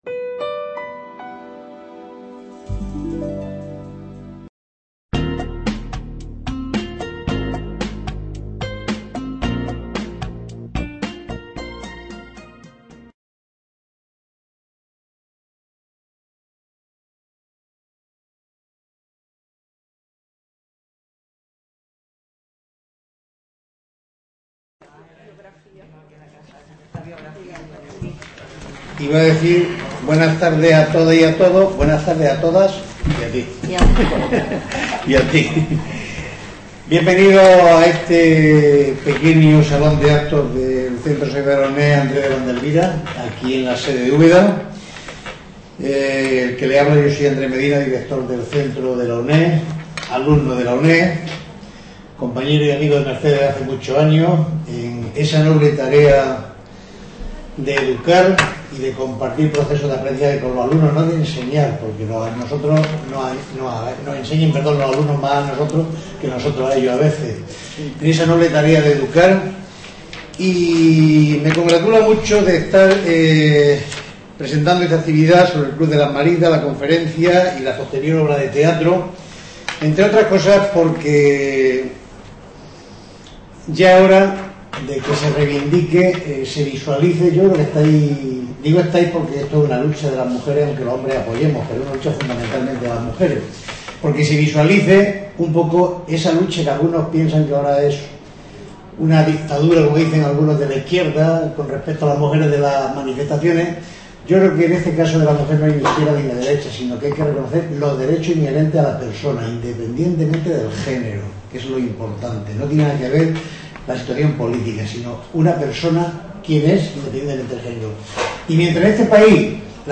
Authorship & License License Rights BY-NC-SA Público Academic Information Room Aula Virtual del Centro Asociado de Jaén Attached Resources Attached Resources Movil Audio Video